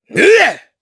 Chase-Vox_Jump_jp.wav